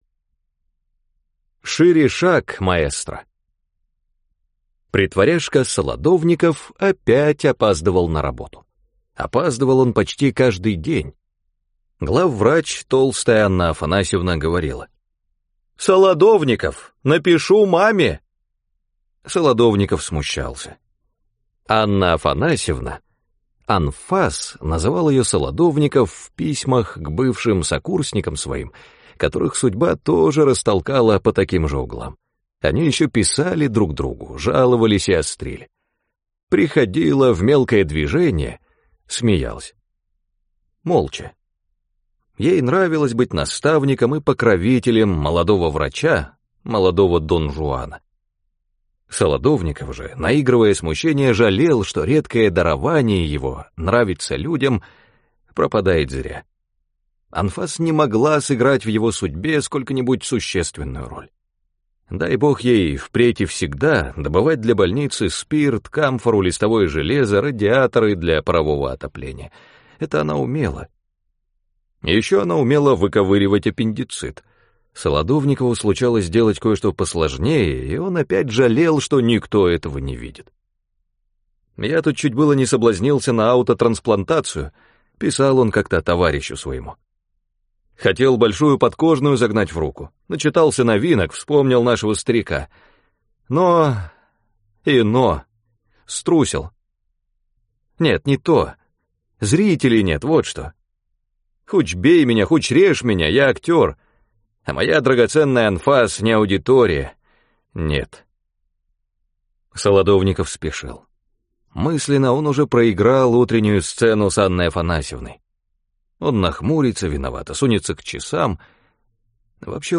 Шире шаг, маэстро! — слушать аудиосказку Василий Шукшин бесплатно онлайн